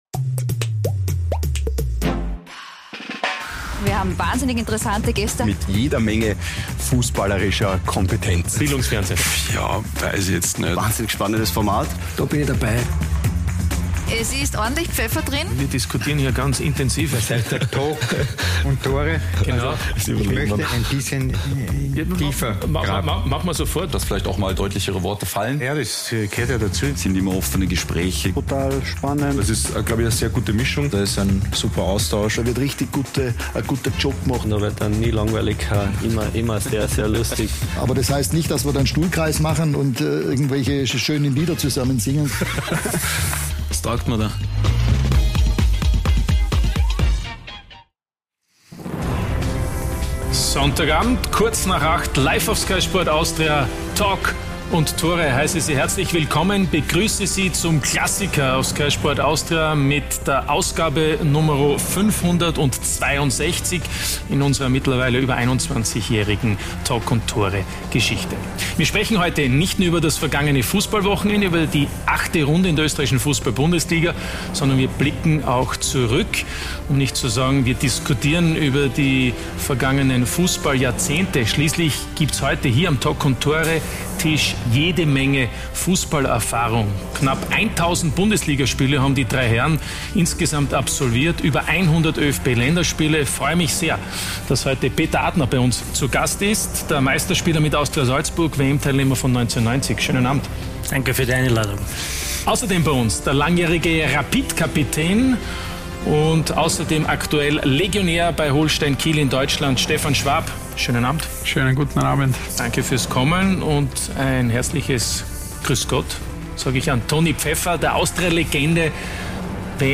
„Talk und Tore“ ist die erste und einzige Fußballtalksendung in Österreich. Wir liefern neue Blickwinkel, Meinungen und Hintergründe zu den aktuellen Themen im österreichischen Fußball und diskutieren mit kompetenten Gästen die aktuellen Entwicklungen.